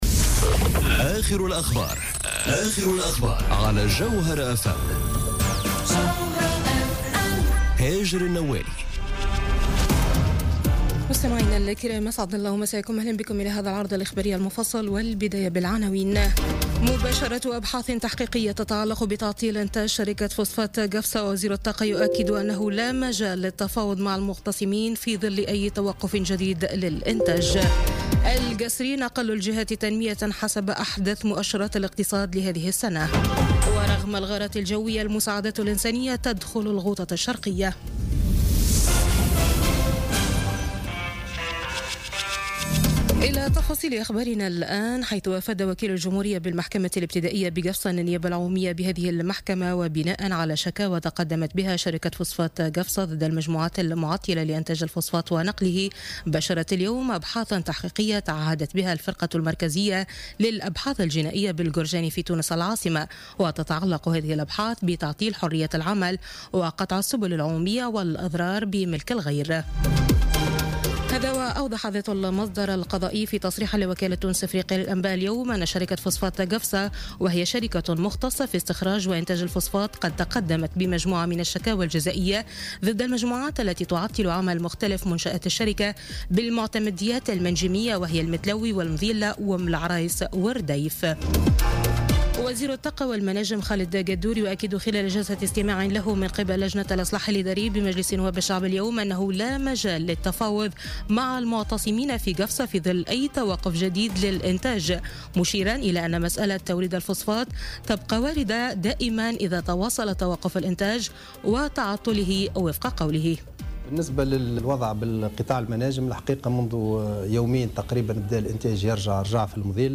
نشرة أخبار السابعة مساء ليوم الاثنين 5 مارس 2018